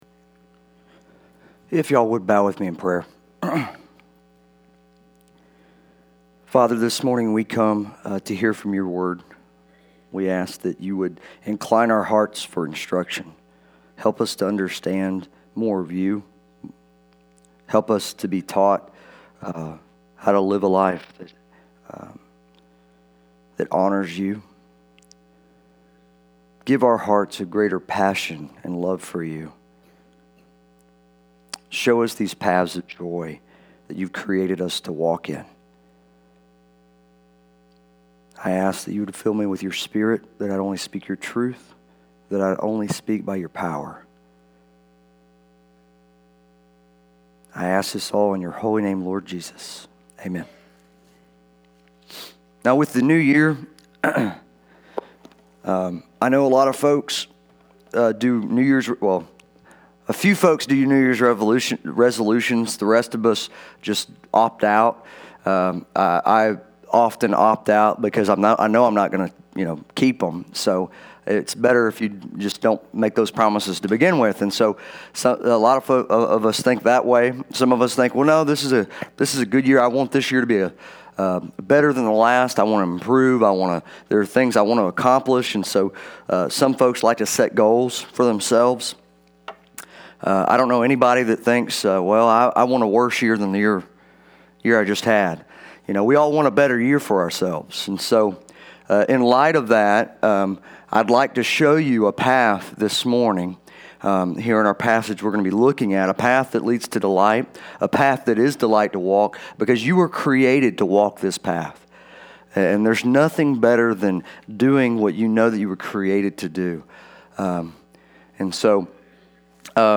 Sermon Audio
Listen to our Sunday morning service!